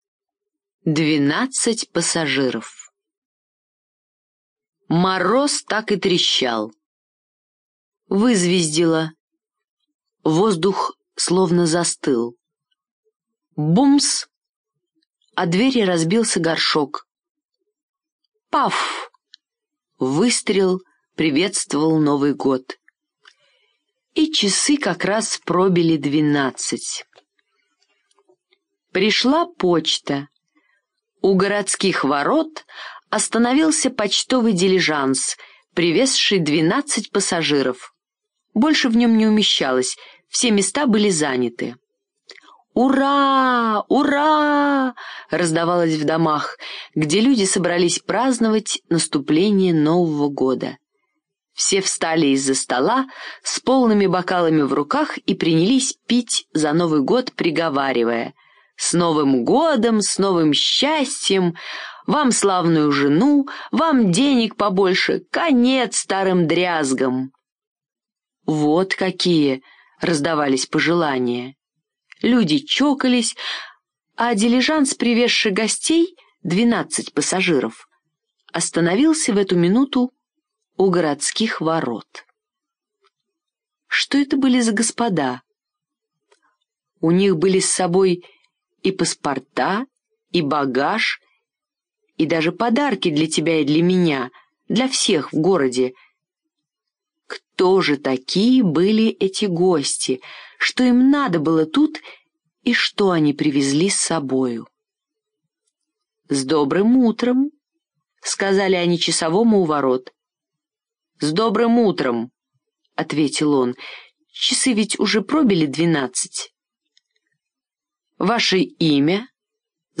Двенадцать пассажиров - аудиосказка Ганса Христиана Андерсена - слушать онлайн